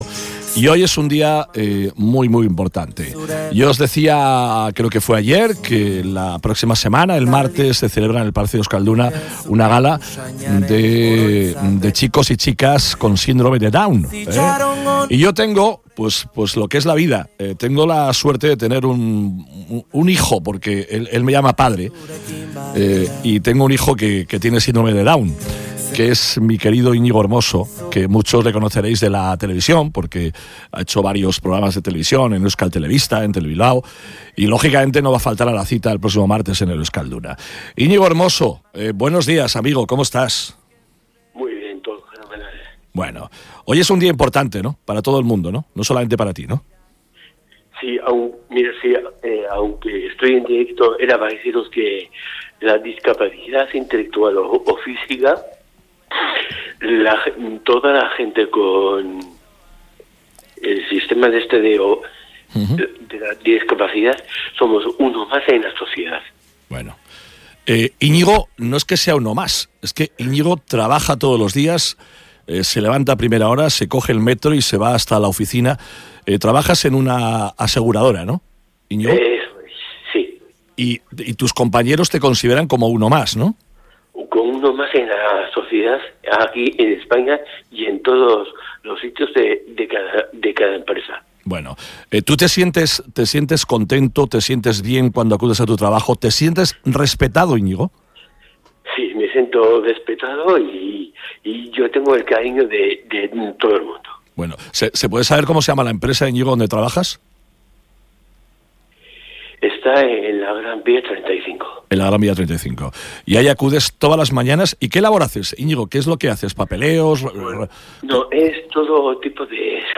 EntrevistasBilbao